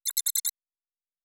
pgs/Assets/Audio/Sci-Fi Sounds/Interface/Data 13.wav at 7452e70b8c5ad2f7daae623e1a952eb18c9caab4